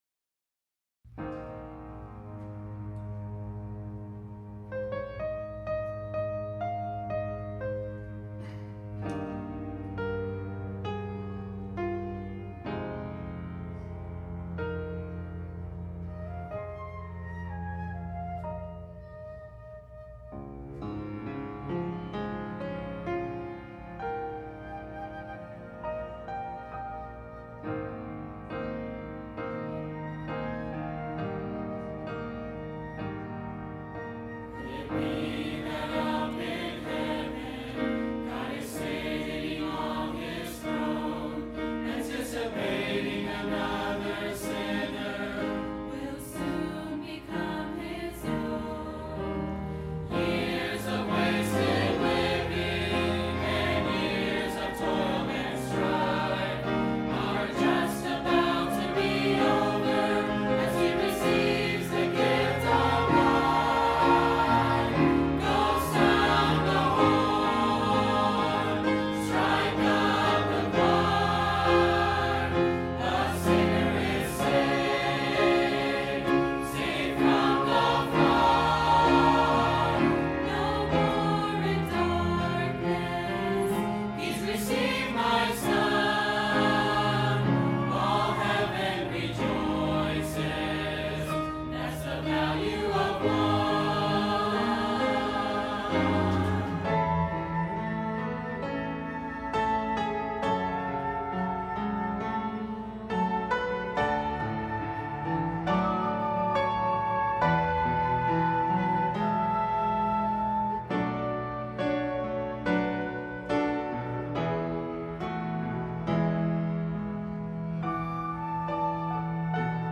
The Value of One | VBC Choir & Orchestra | September 2023 | Banned But Not Bound
by VBC Choir & Orchestra | Verity Baptist Church